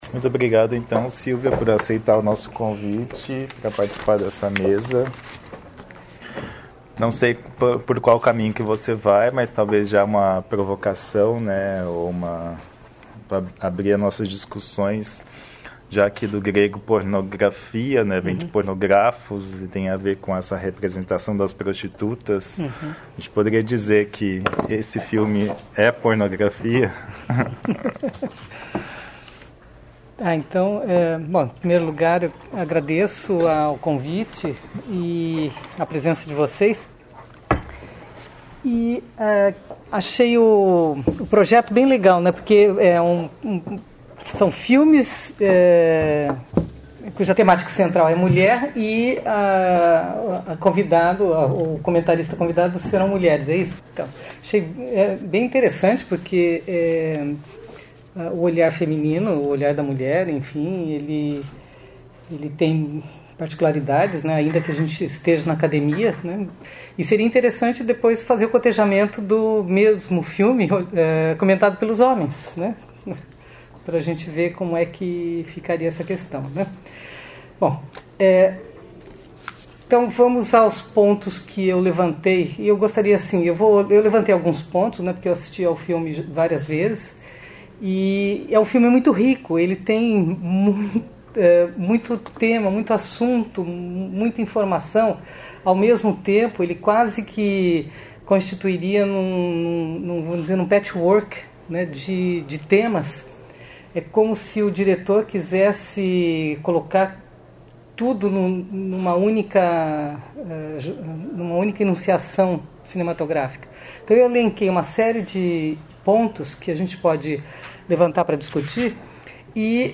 realizada em 22 de outubro de 2015 no Auditório "Elke Hering" da Biblioteca Central da UFSC